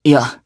Theo-Vox-Deny_jp.wav